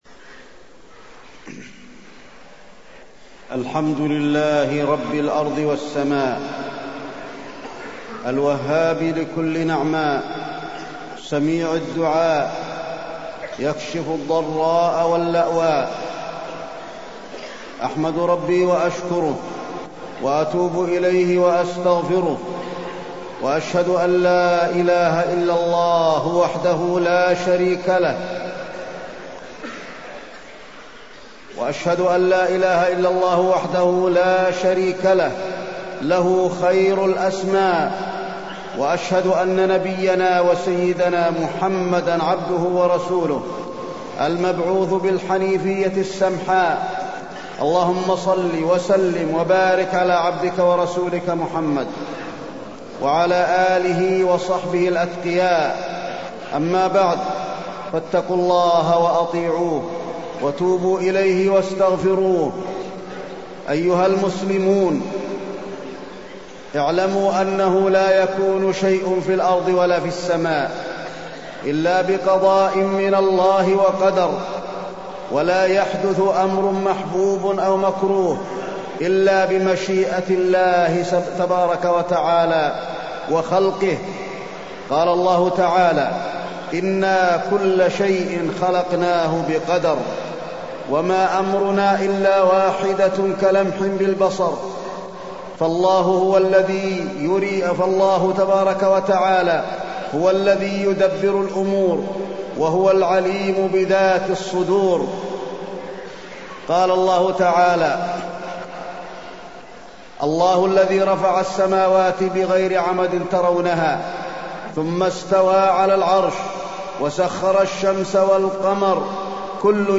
تاريخ النشر ٢٠ ذو الحجة ١٤٢٣ هـ المكان: المسجد النبوي الشيخ: فضيلة الشيخ د. علي بن عبدالرحمن الحذيفي فضيلة الشيخ د. علي بن عبدالرحمن الحذيفي الدعاء